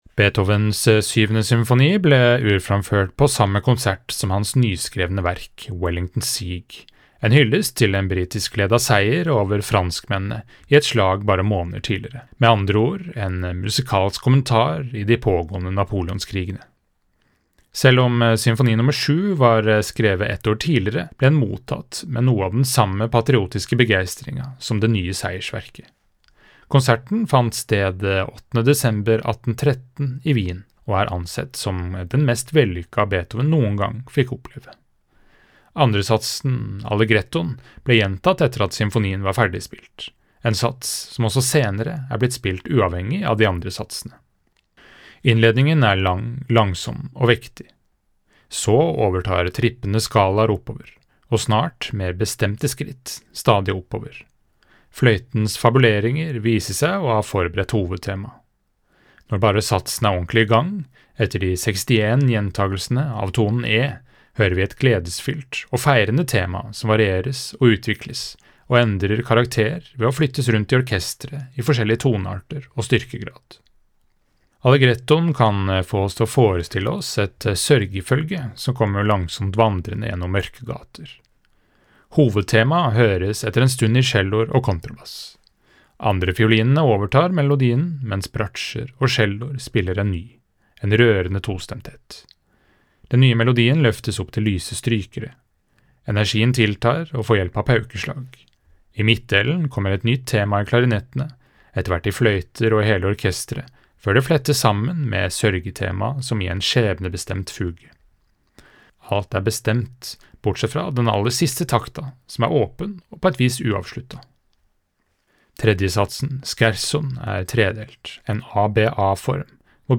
VERKOMTALE-Beethovens-Symfoni-nr-7.mp3